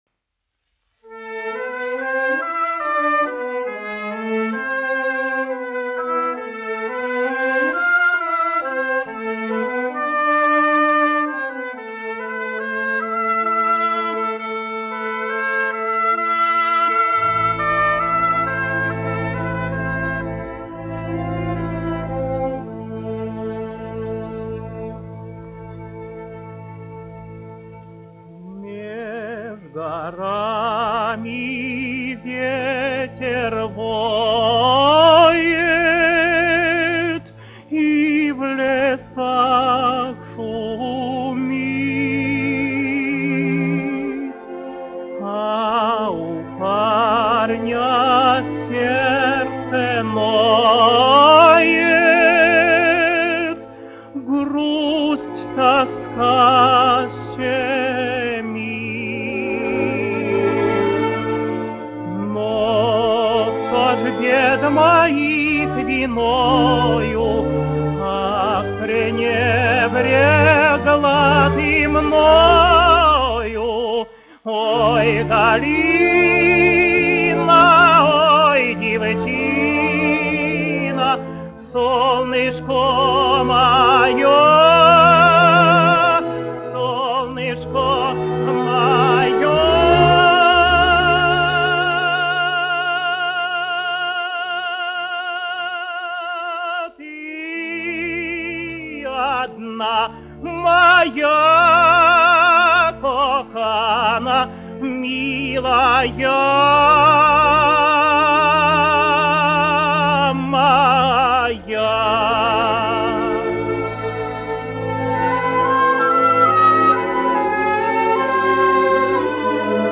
Козловский, Иван Семенович, 1900-1993, dziedātājs
Александр Иванович Орлов, diriģents
1 skpl. : analogs, 78 apgr/min, mono ; 25 cm
Operas--Fragmenti
Latvijas vēsturiskie šellaka skaņuplašu ieraksti (Kolekcija)